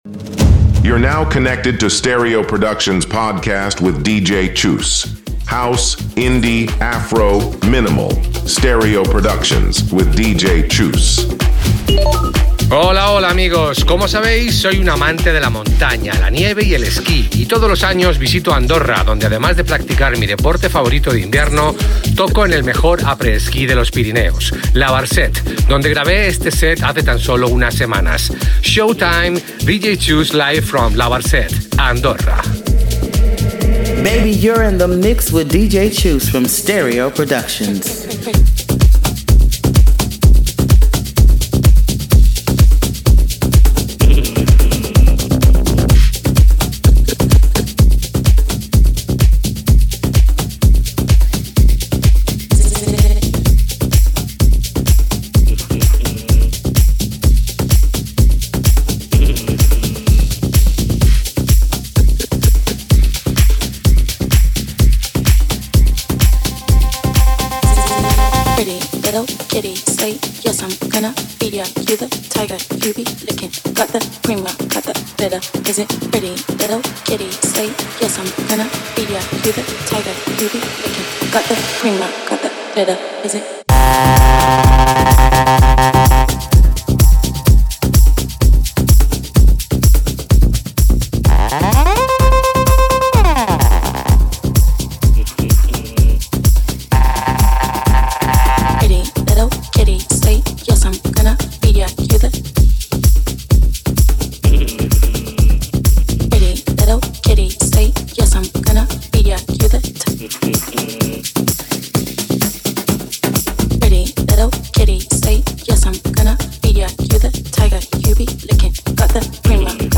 high-energy set packed with house bangers